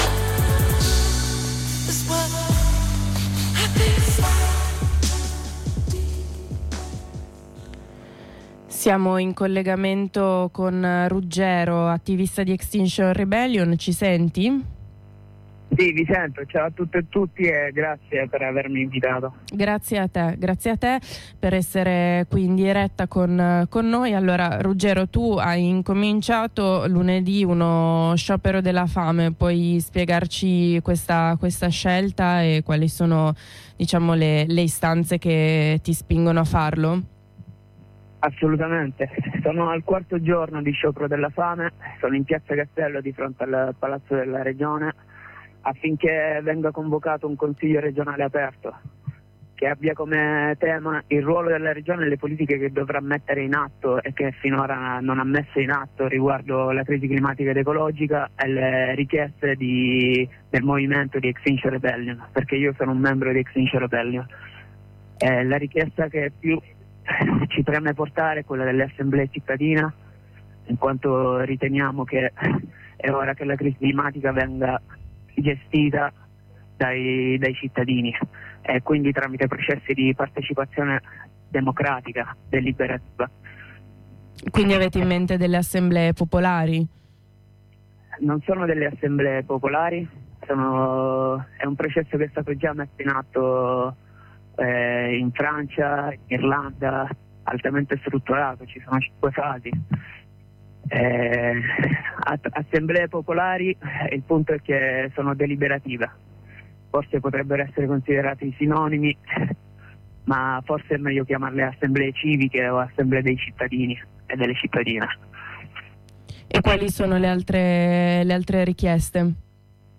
attivista che abbiamo sentito ai microfoni di Radio Blackout